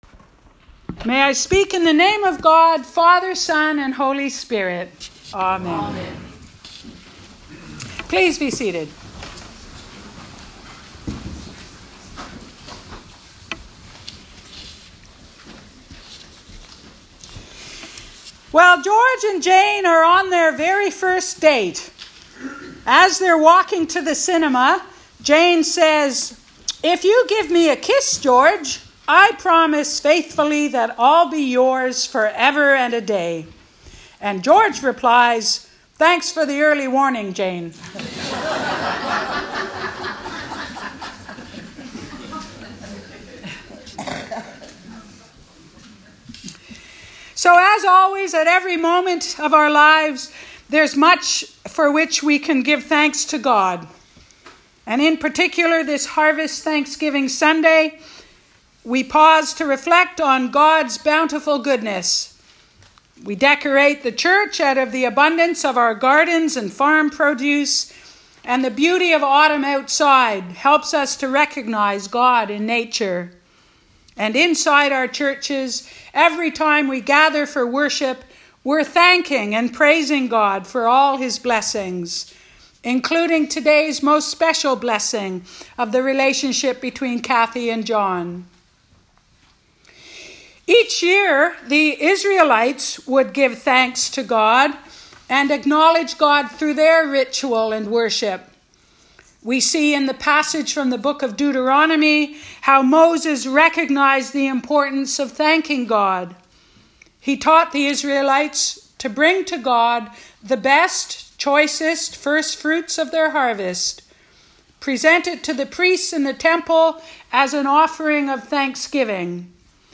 Preached on Thanksgiving Sunday at the Church of the Ascension, Killaloe.